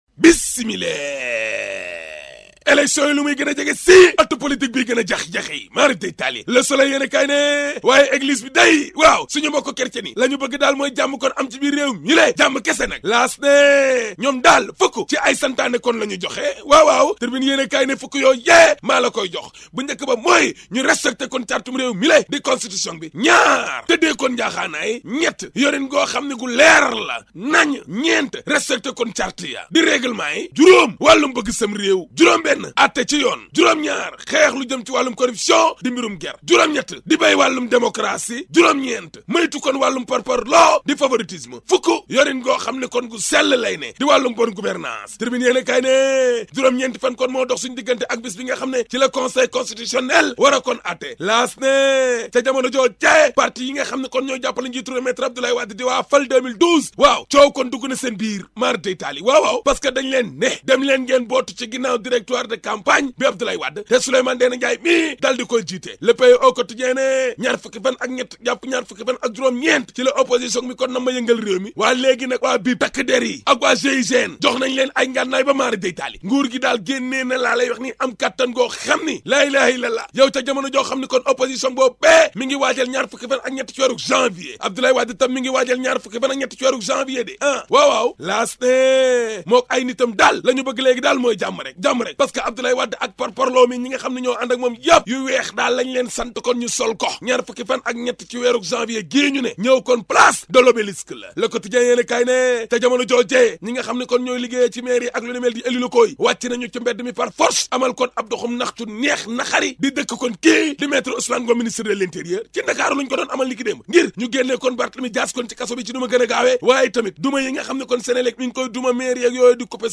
La revue de presse d'Ahmed Aidara du 20 Janvier (ZIK FM)